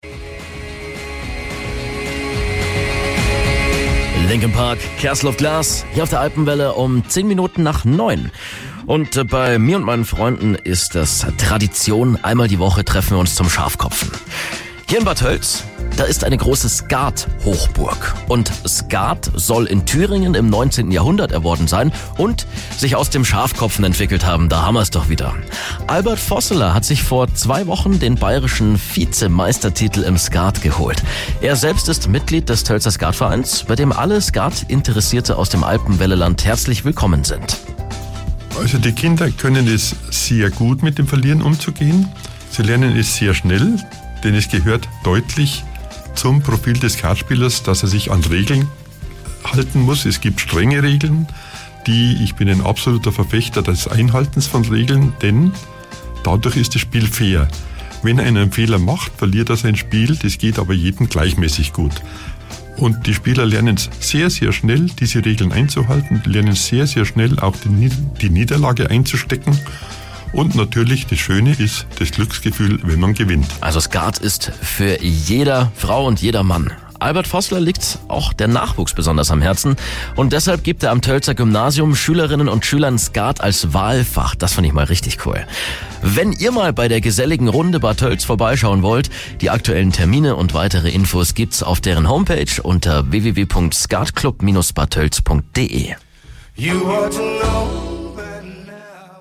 Morgens um 6.10 Uhr ging es los mit den jeweils knapp 2-minütigen Blöcken, die aus dem nahezu einstündigen Interview (siehe auch unten!) zusammengeschnitten wurden.